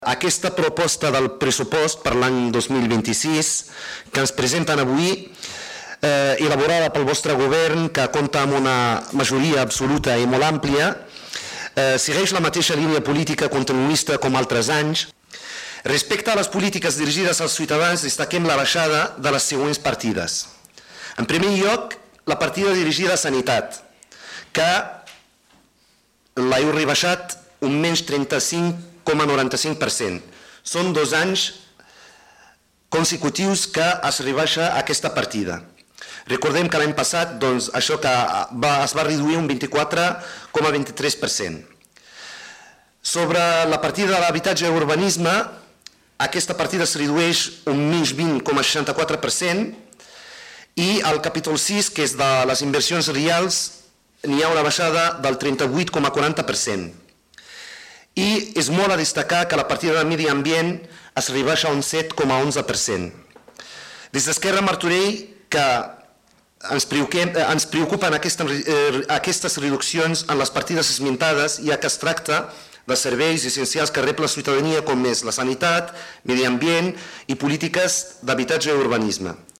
Soulimane Messaoudi, regidor d'ERC
Ple-Municipal-Desembre-04.-Soulimane.mp3